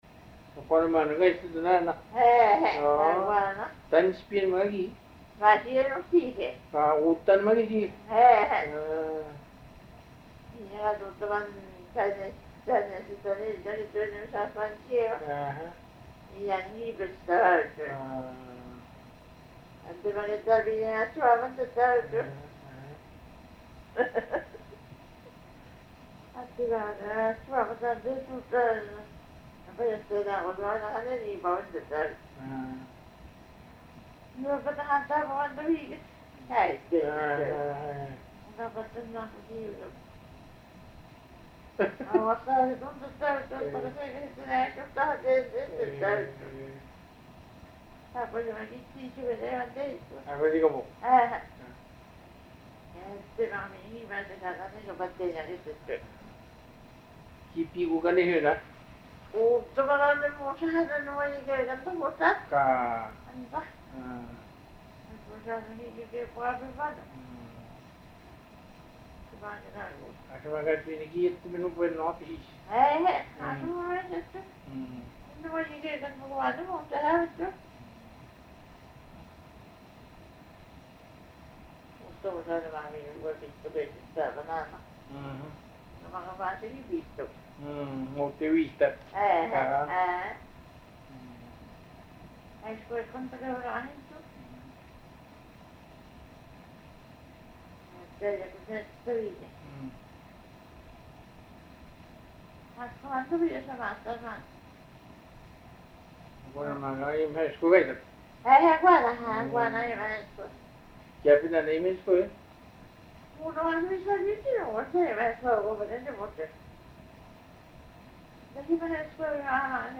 Interview
(Restored)